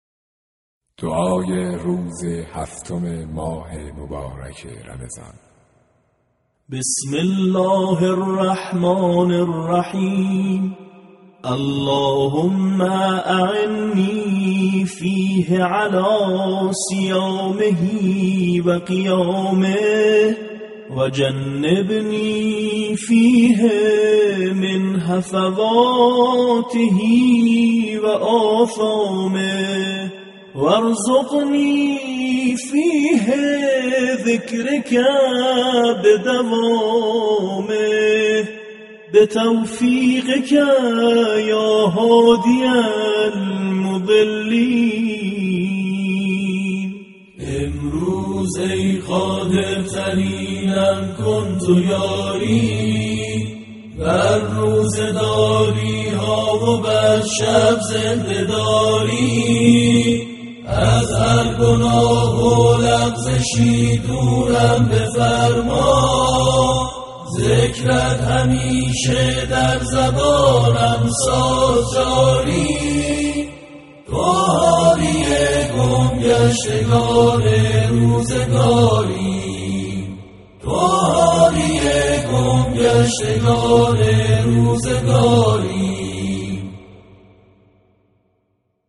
برچسب ها: خبرگزاری قرآن ، خبرگزاری ایکنا ، چندرسانه ای ، دعای روز هفتم ، ماه مبارک رمضان ، ادعیه خوانی ، صوت ، iqna